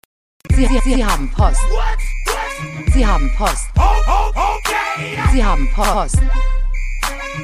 “Sie haben Post” meme sound, classic German “You’ve got mail” clip used for notifications, humor, and nostalgic reactions.